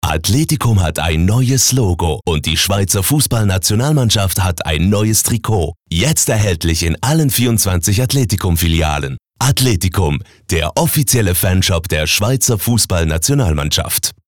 Werbung Hochdeutsch (CH)
Sprecher mit breitem Einsatzspektrum.